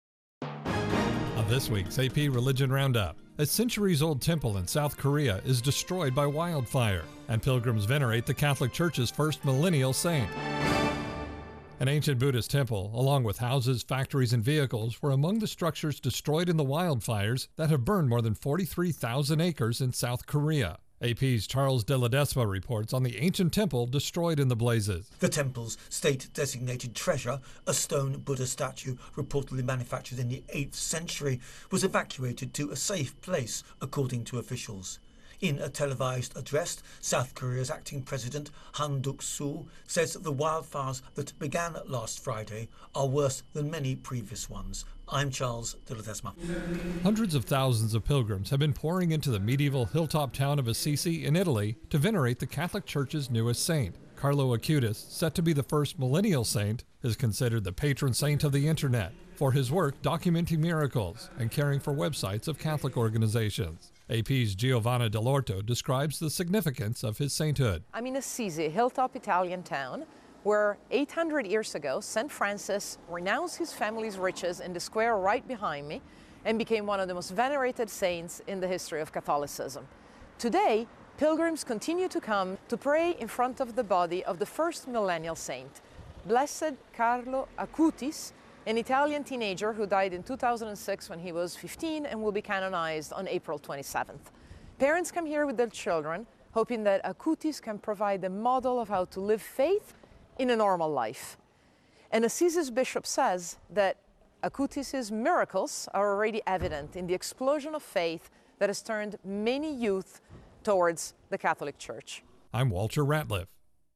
On this week's AP Religion Roundup, a centuries old temple in South Korea is destroyed by wildfire, and pilgrims venerate the Catholic church's first millennial saint.